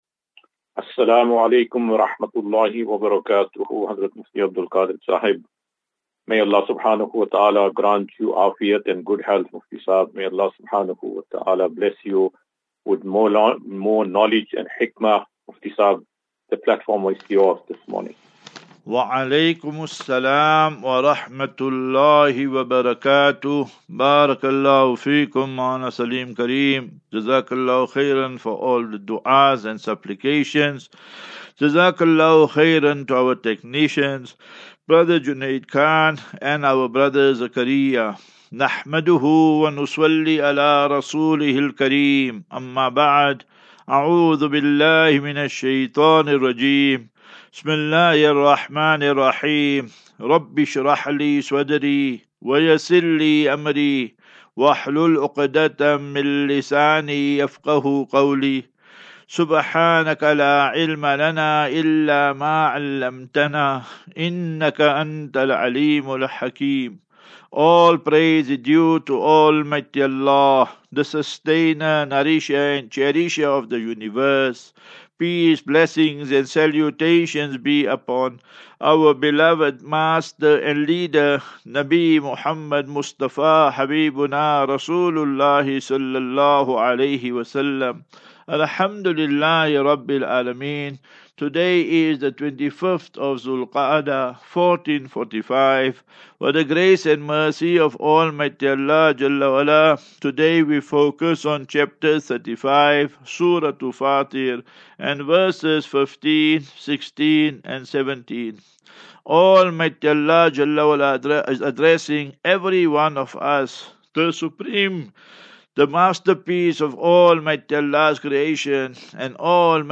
As Safinatu Ilal Jannah Naseeha and Q and A 3 Jun 03 June 2024.